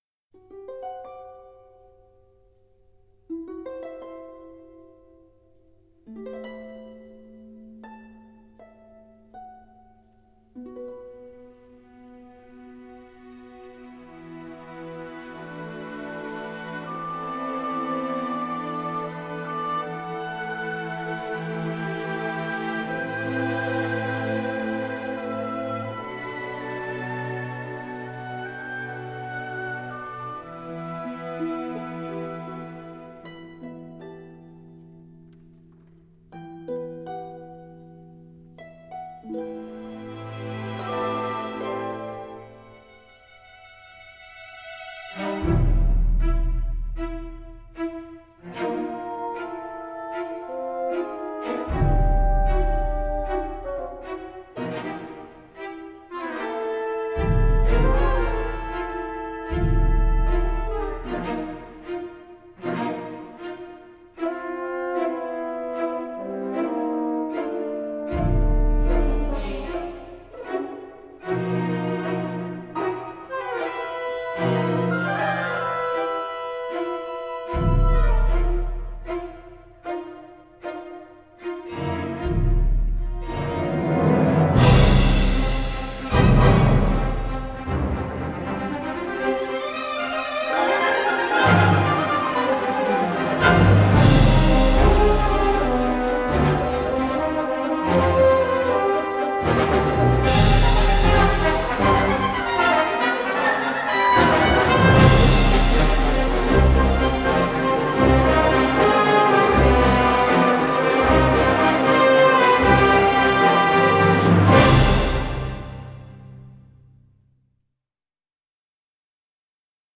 Жанр: Soundtrack